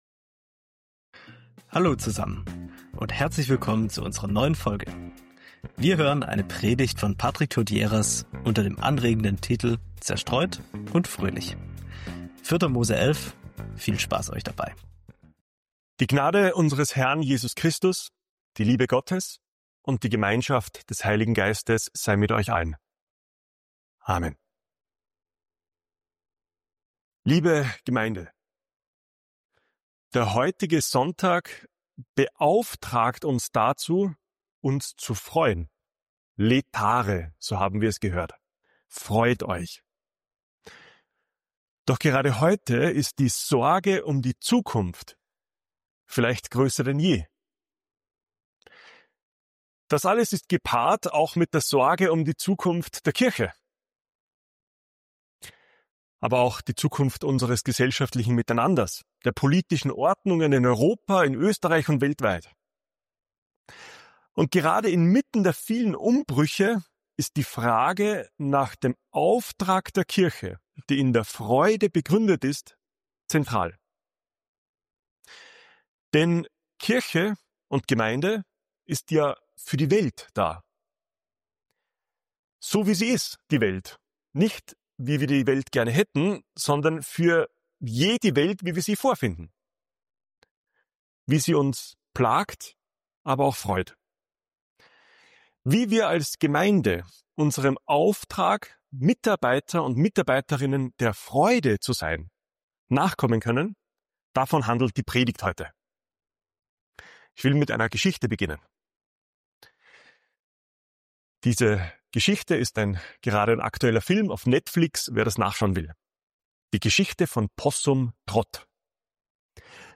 Predigen Podcast